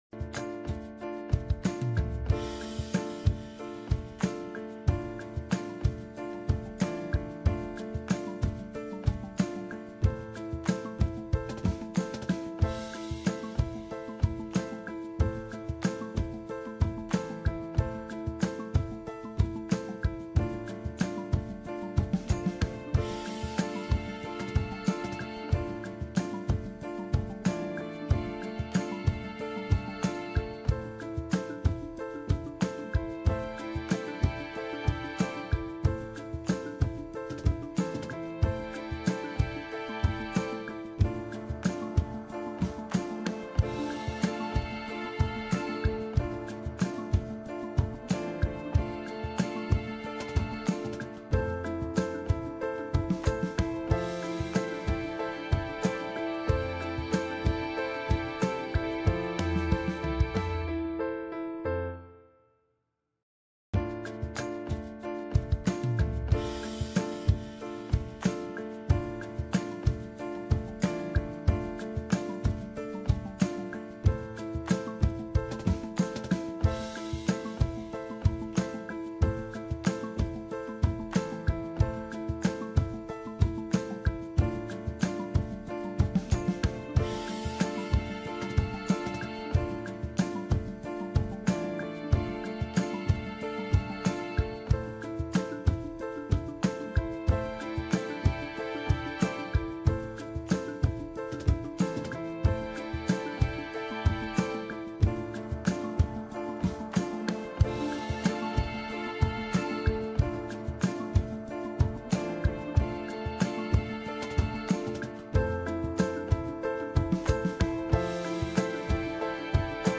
Эфир ведет Александр Плющев